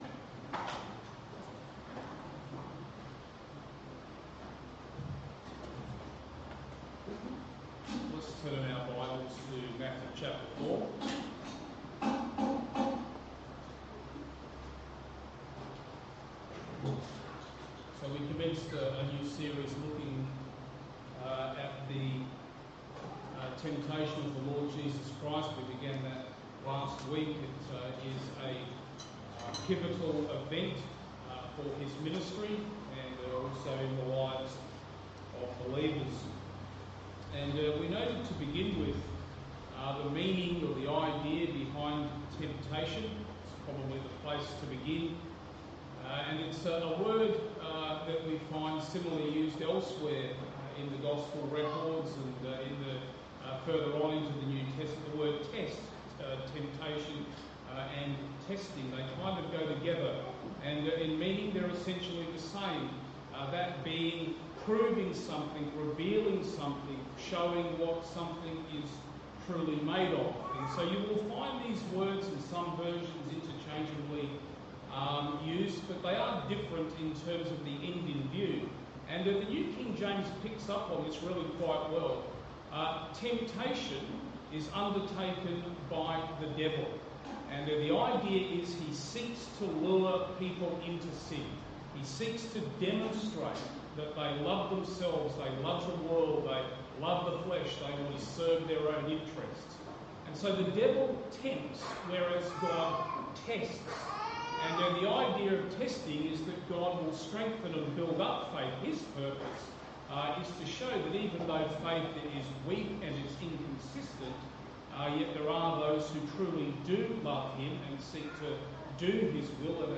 Sermons , The Temptation of Jesus